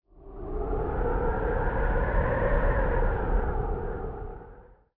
windgust3.wav